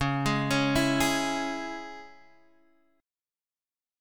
C#m chord